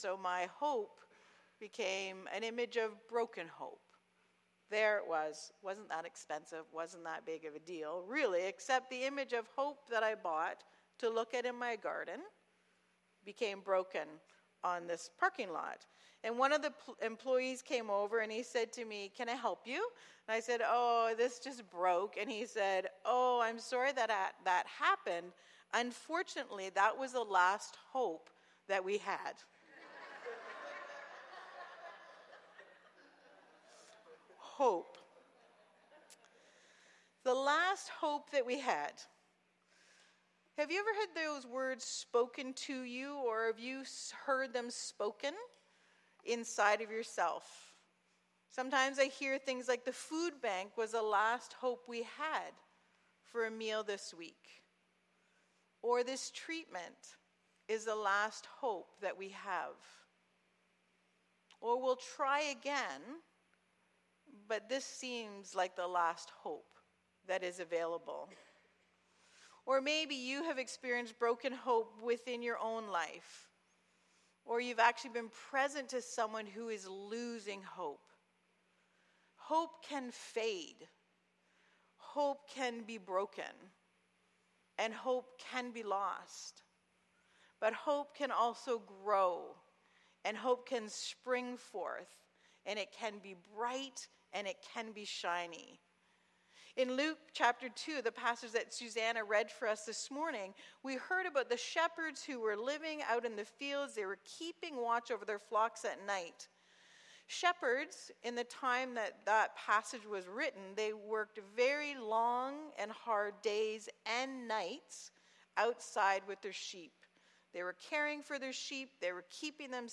Sermons | The Gathering Church